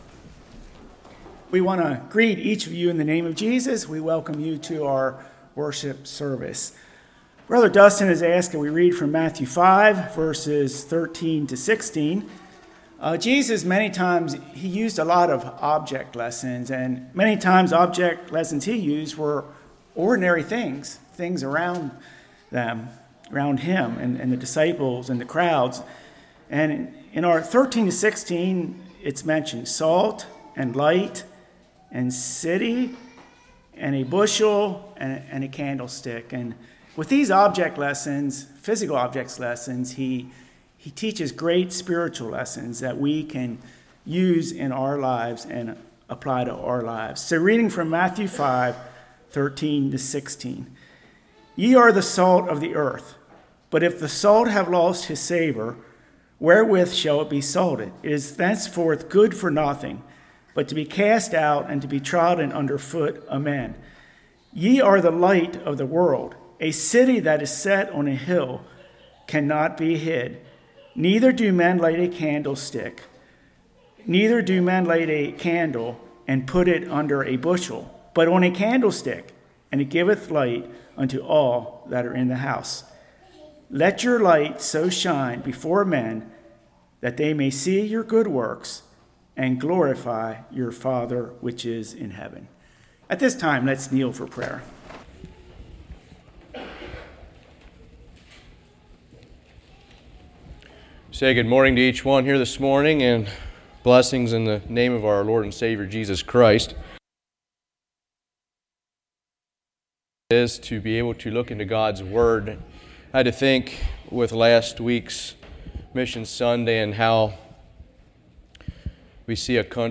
Matthew 5:13-16 Service Type: Morning Characteristics of salt Characteristics of light Light vs darkness Where are we to shine?